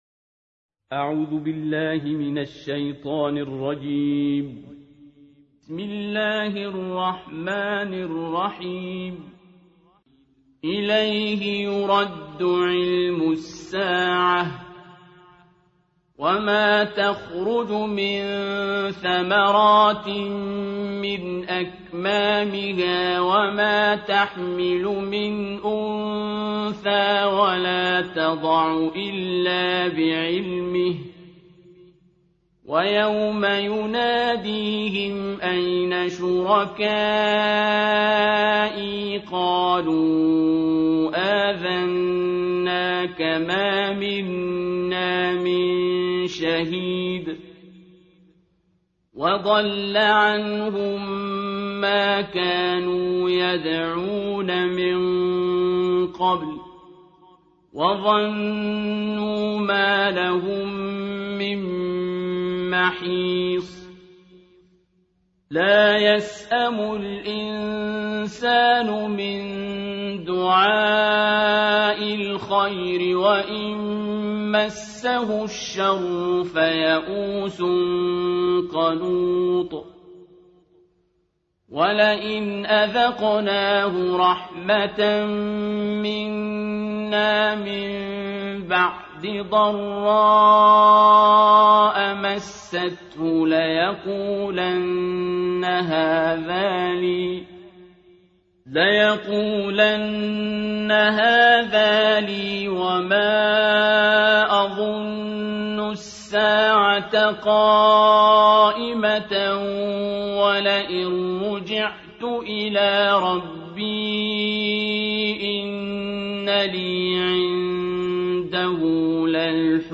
ترتیل جزء بیست و پنجم قرآن کریم - استاد عبدالباسط
دانلود ترتیل جزء بیست و پنجم قرآن کریم با صدای استاد عبدالباسط محمد عبدالصمد
در این بخش از ضیاءالصالحین، ترتیل جزء بیست و پنجم قرآن کریم را با قرائت دلنشین استاد عبدالصمد عبدالباسط با علاقه مندان به قرآن کریم به اشتراک می گذاریم.